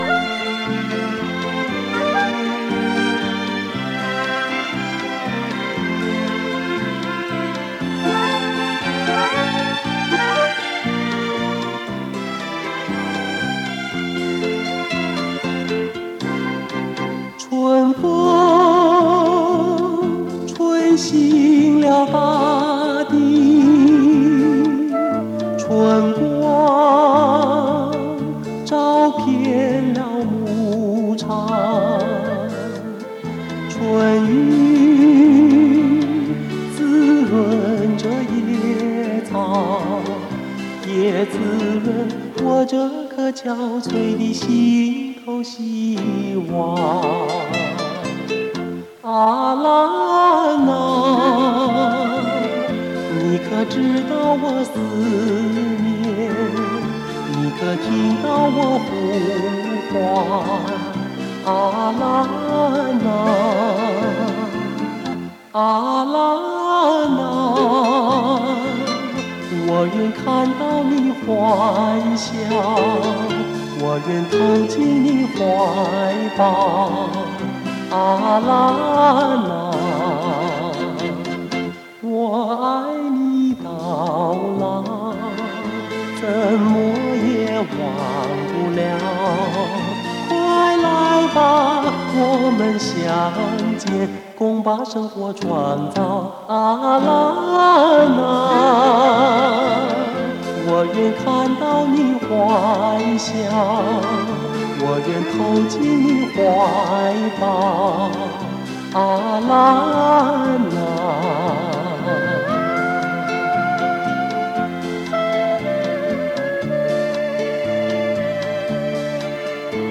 重新录制 原音重现